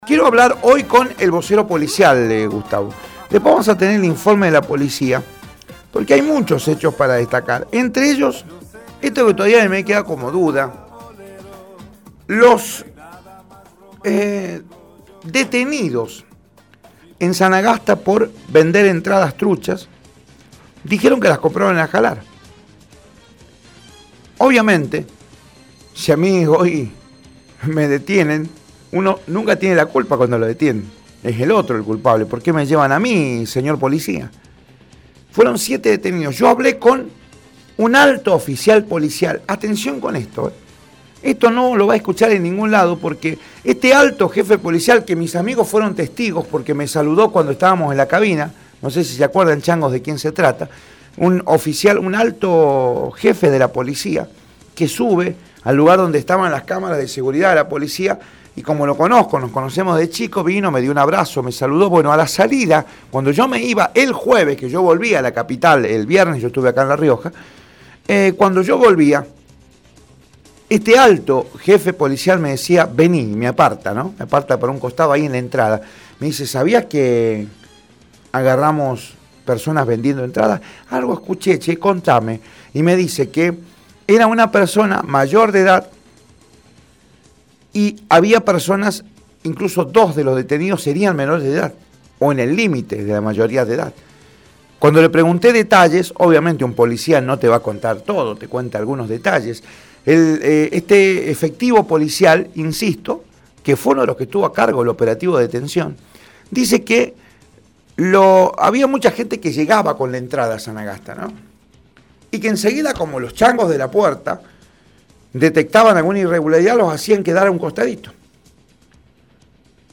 Informe de Radio Rioja